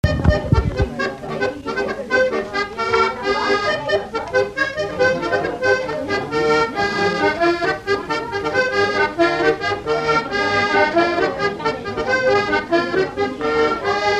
Note maraîchine
Divertissements d'adultes - Couplets à danser
danse : branle : courante, maraîchine
Pièce musicale inédite